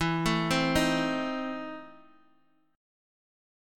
EM7sus4#5 Chord